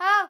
Damsel Female - Spelunky
damsel-female-spelunky.mp3